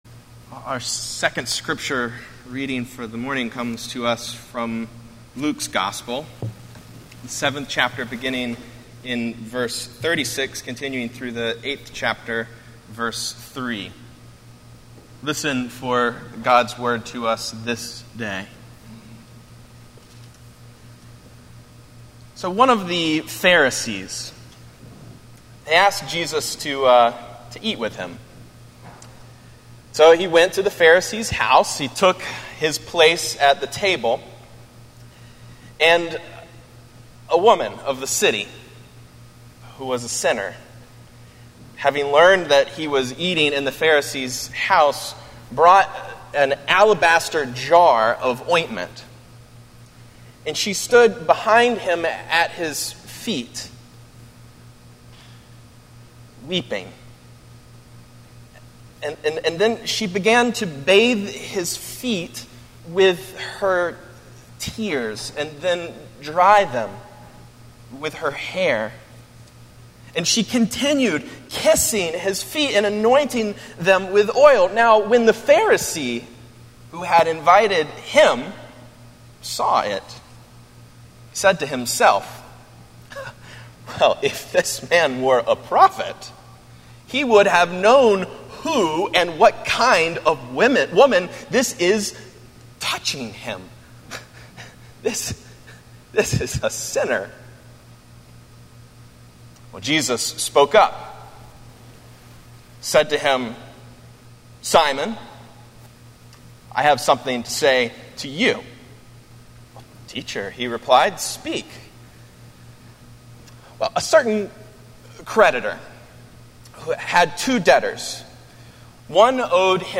Morningside Presbyterian Church - Atlanta, GA: Sermons: When Your Sins Are Many